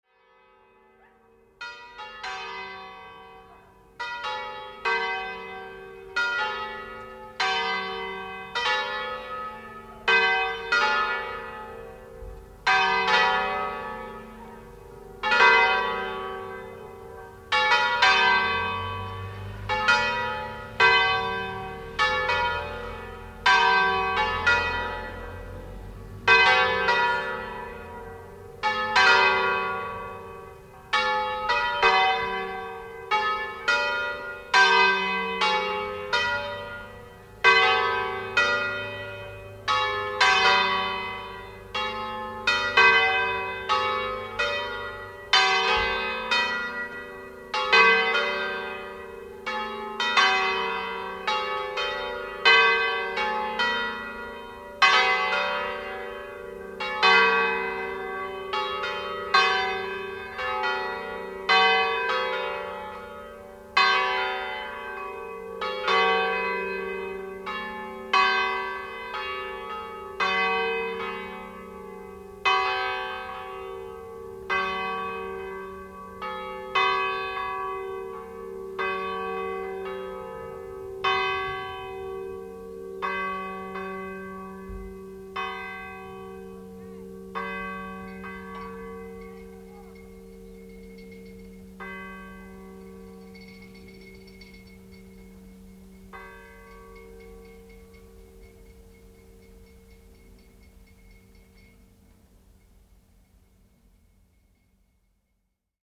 Le campane di Santa Maria Della Versa
campanile-santa-maria-della-versa.mp3